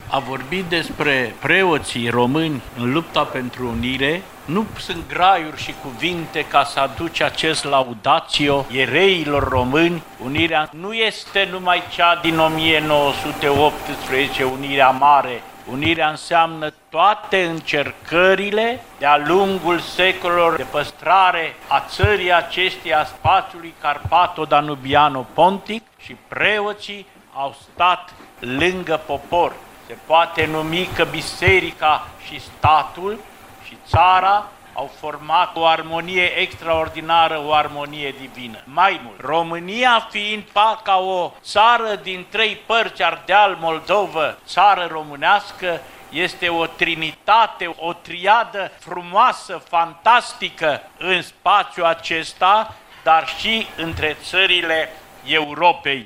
La Clubul Ţăranului Român
Contribuţia slujitorilor bisericii la înfăptuirea Marii Uniri din 1918 a fost evocată săptămâna aceasta în cadrul conferinţei „Preoţii Unirii”, organizată la Clubul Ţăranului Român.